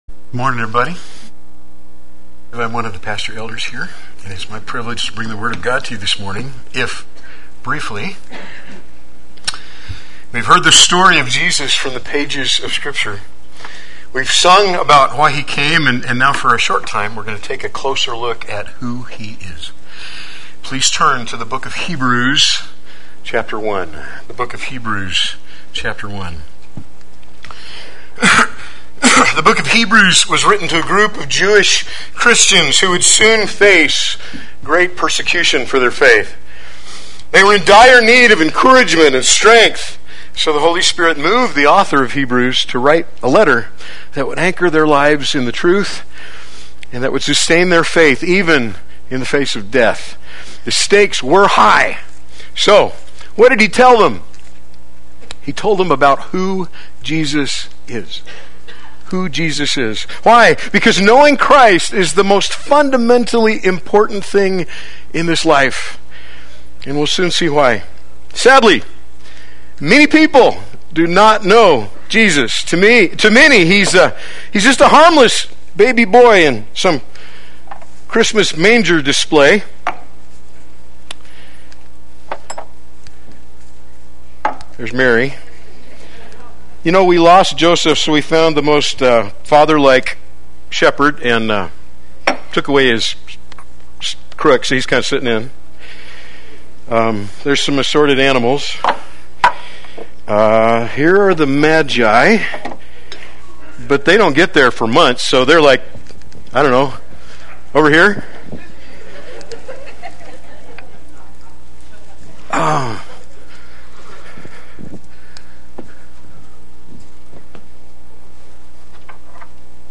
Play Sermon Get HCF Teaching Automatically.
Sunday Worship